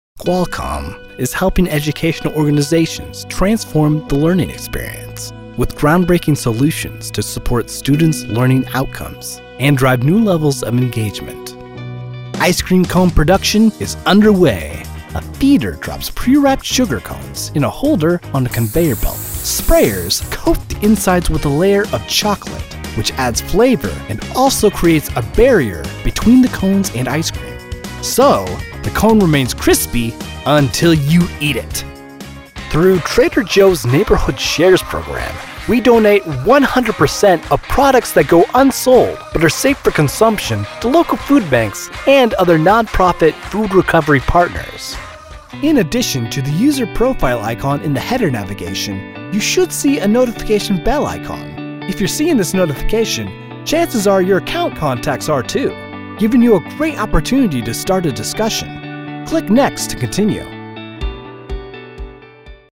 Commercial Demo
Midwestern, General
Young Adult
Middle Aged